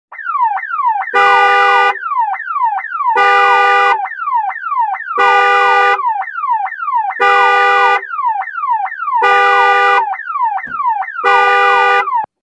zvuk-signalizacii-mashiny_006
zvuk-signalizacii-mashiny_006.mp3